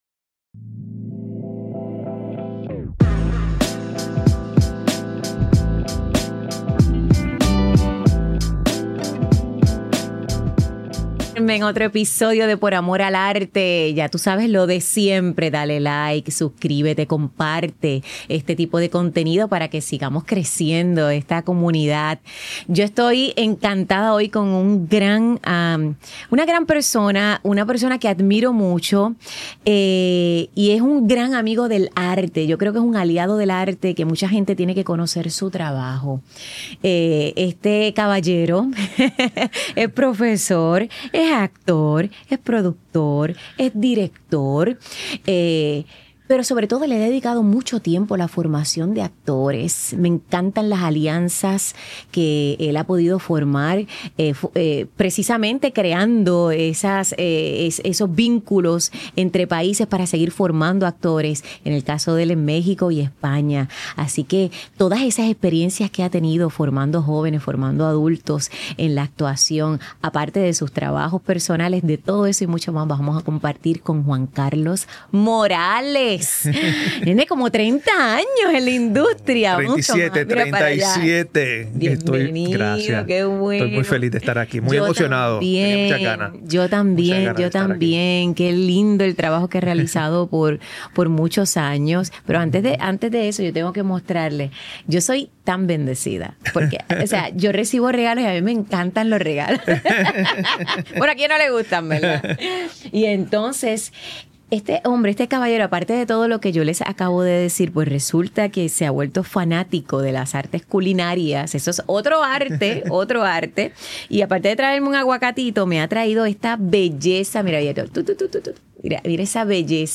También nos enseña la importancia de la labor del 'coach' de actuación y qué reglas aplica a la hora de colaborar con actores de teatro y cine. Su basta experiencia nos permite abordar temas sobre las técnicas de actuación y manejo de emociones. Hablamos sobre su pasión por la cocina, futuros proyectos y mucho más ¡No te pierdas esta entrevista! Grabado en GW-Cinco Studio para GW5 Network Stylist